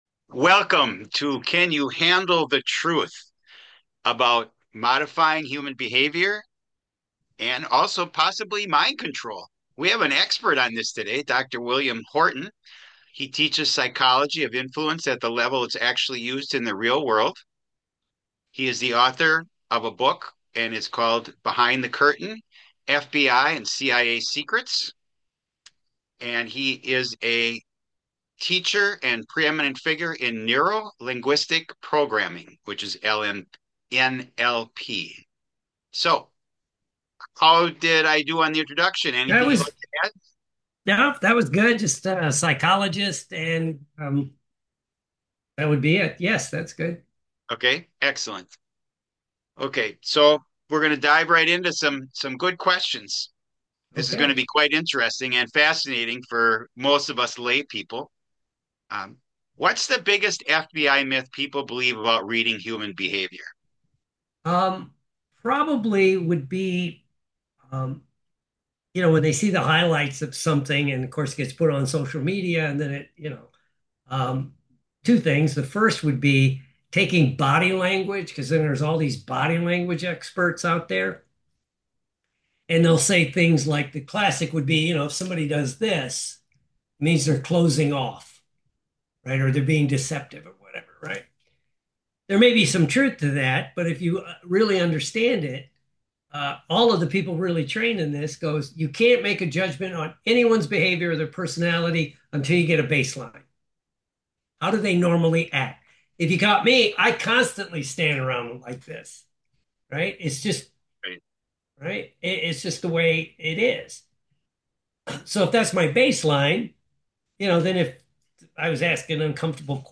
sits down with psychologist of influence